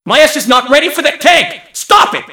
mvm_tank_alerts07.mp3